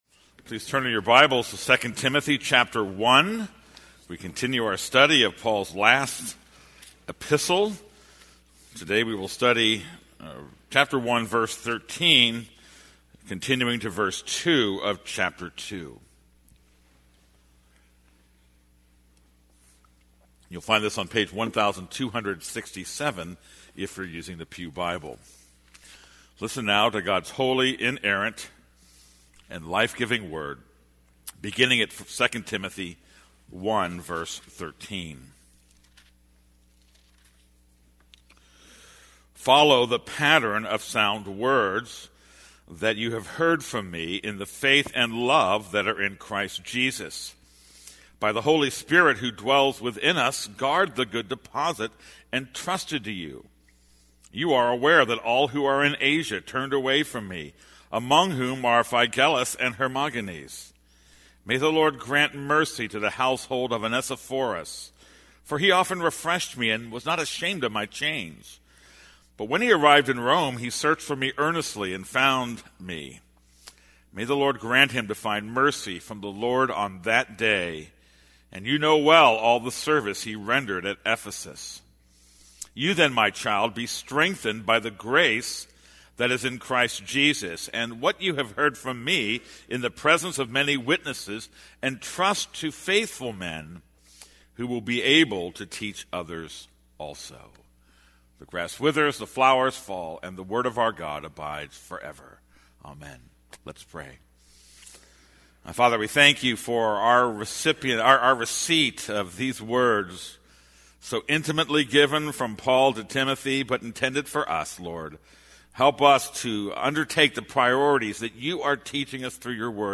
This is a sermon on 2 Timothy 1:13-2:2.